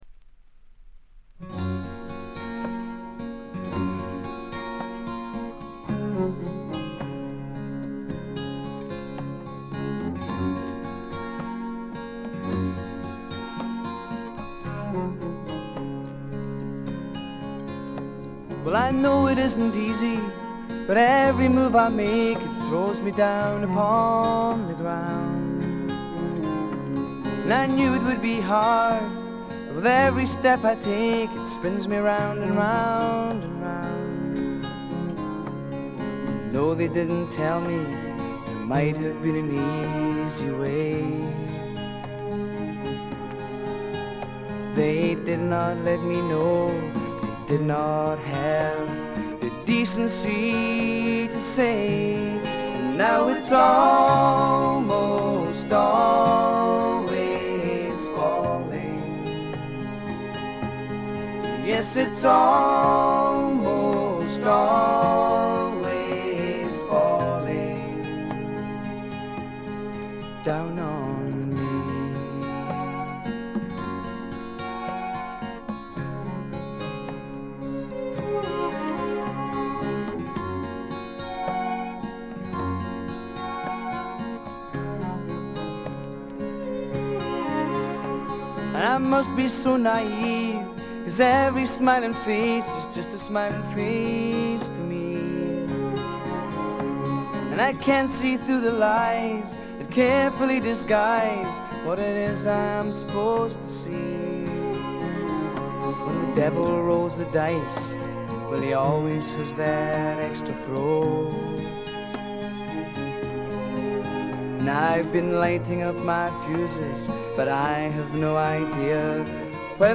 ほとんどノイズ感無し。
試聴曲は現品からの取り込み音源です。
Recorded At Pace Studios, Milton Keynes